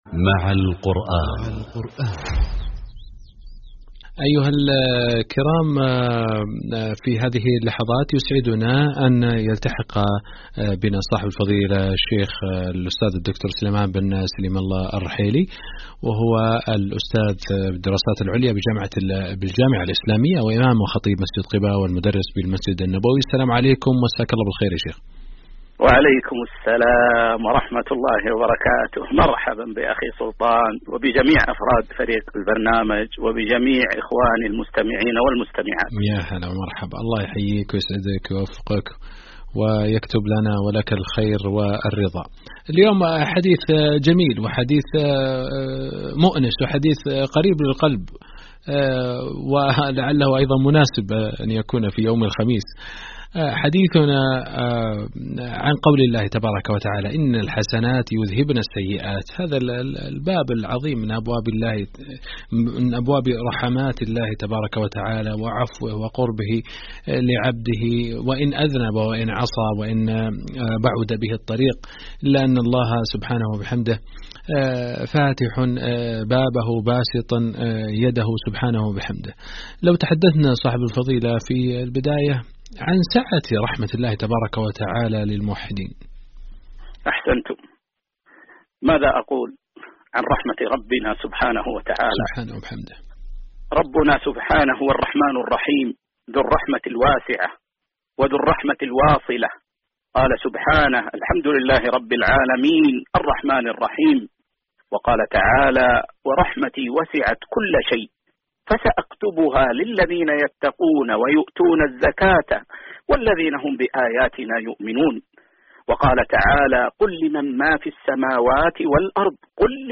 برنامج مع القرآن عبر إذاعة القرآن الكريم